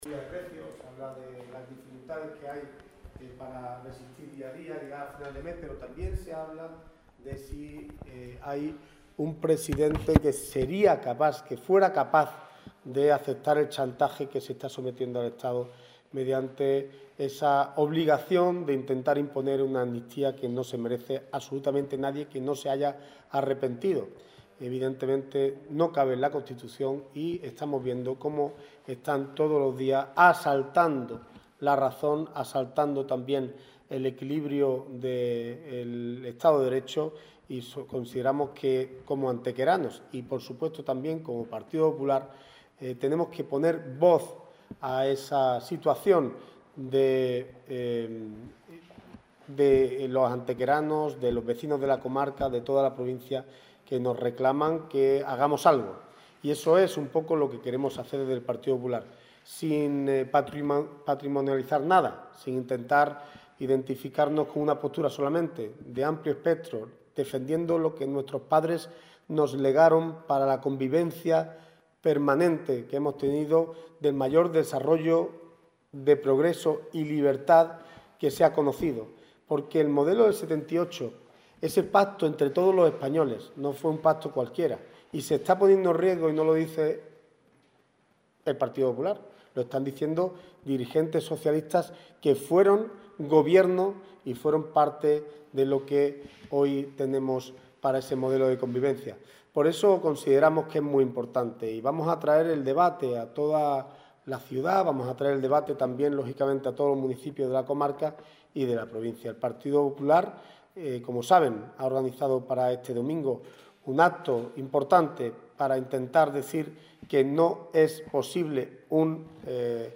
Carmona, que ha ofrecido hoy una rueda de prensa en Antequera junto a la portavoz municipal, Ana Cebrián, ha asegurado que “el Partido Popular se alza como partido de Estado y suma la voz de muchas personas que, sin ser votantes del PP, tampoco aceptan este chantaje y quieren manifestar su postura”.